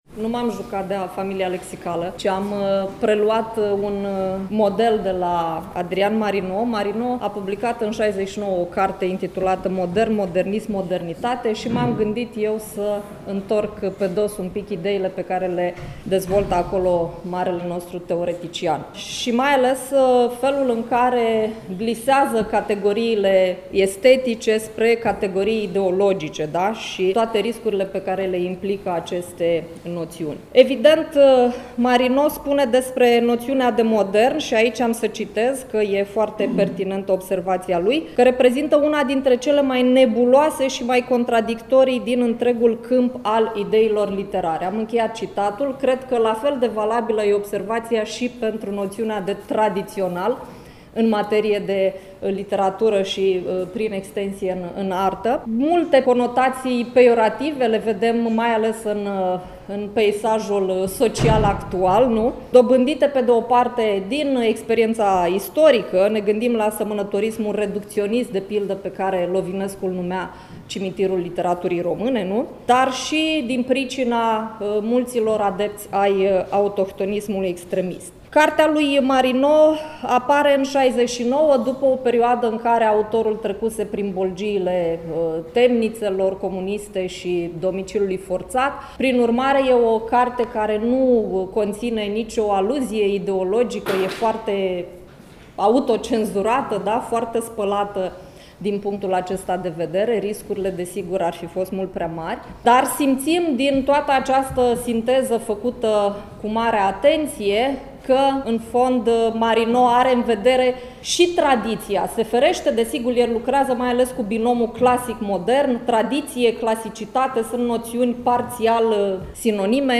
În a doua zi a festivalului, 11 decembrie 2024, începând cu ora 13, s-a desfășurat sesiunea de comunicări „Literatură și Tradiție la Iași”.
Sesiunea a avut loc în Sala „Ștefan Procopiu” din incinta Muzeului Științei și Tehnicii din cadrul Complexului Muzeal Național „Moldova” Iași.